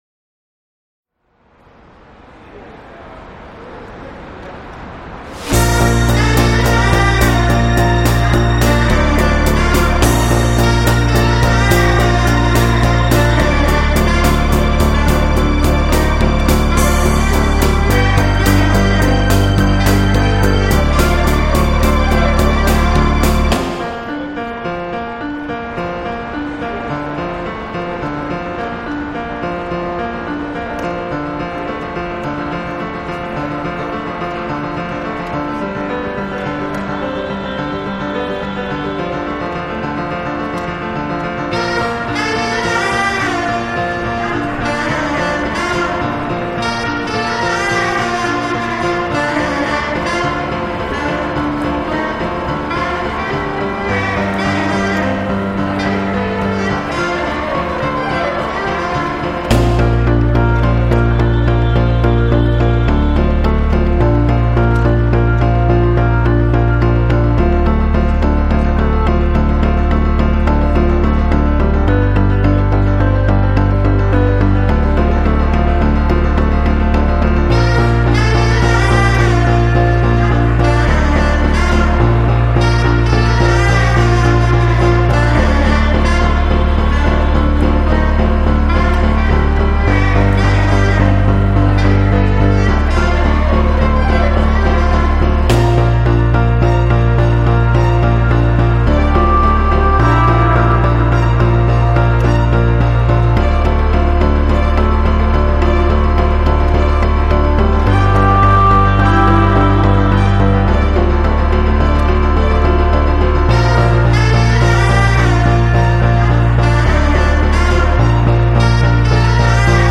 High Line saxophonist reimagined